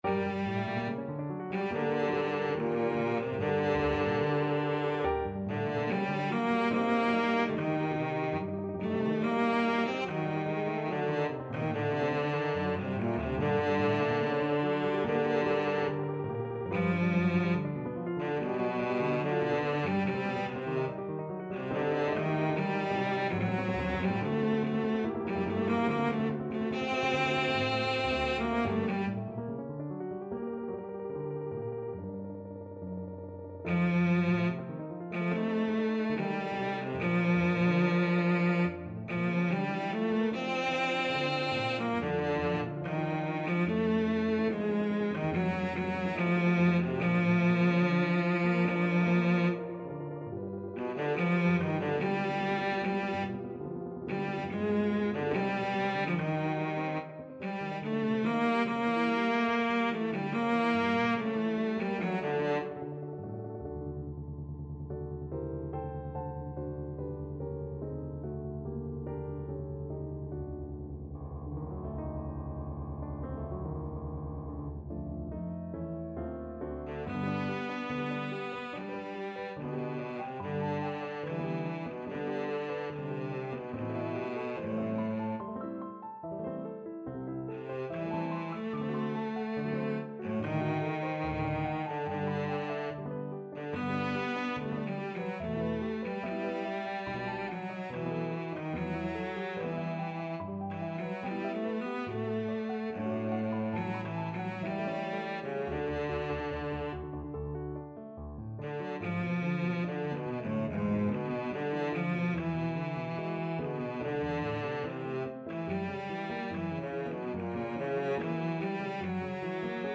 Pagliaccio no son from Pagliacci Cello version
Cello
Allegro moderato (=144) (View more music marked Allegro)
G minor (Sounding Pitch) (View more G minor Music for Cello )
4/4 (View more 4/4 Music)
G3-D5
Classical (View more Classical Cello Music)